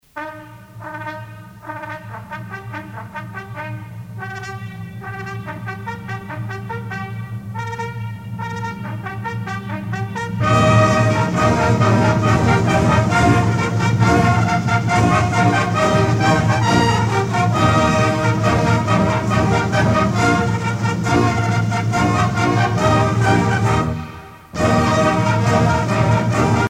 circonstance : militaire
Genre strophique
Pièce musicale éditée